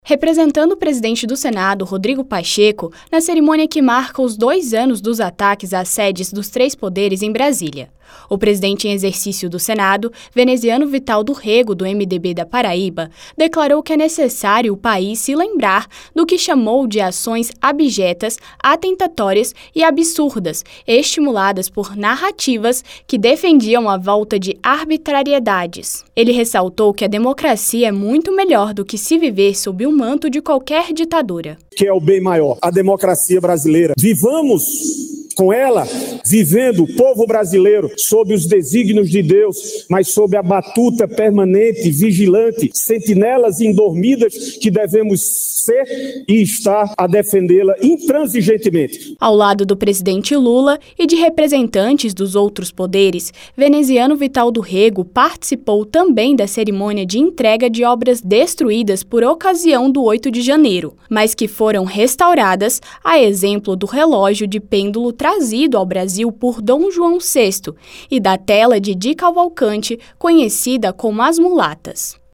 Pronunciamento
Em cerimônia que marcou os dois anos dos ataques do 8 de Janeiro, presidente em exercício do Senado diz que democracia é melhor do que se viver em qualquer ditadura.